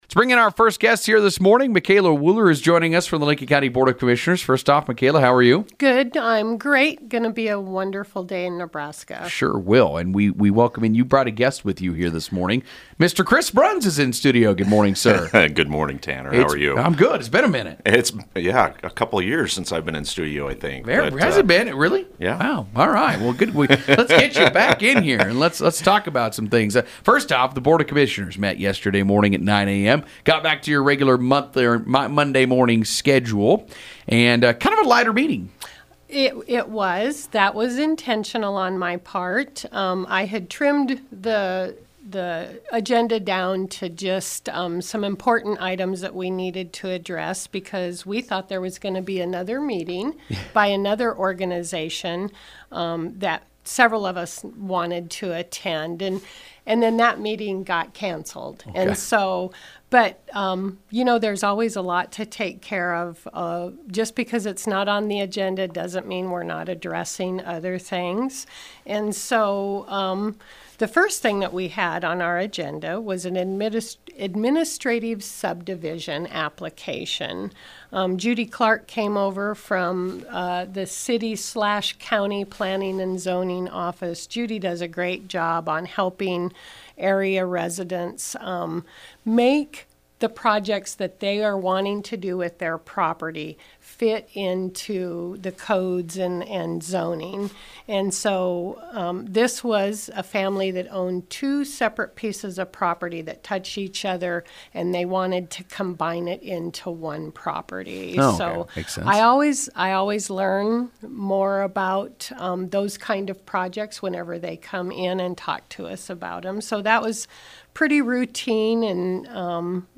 The Lincoln County Board of Commissioners met on Monday for their regular weekly meeting and Micaela Wuehler, the chair recapped the meeting on Huskeradio’s Mugs in the Morning on Tuesday.
Included in the conversation was a presentation by Pinpoint, an internet provider regarding the broadband expansion project. Hear the full interview below!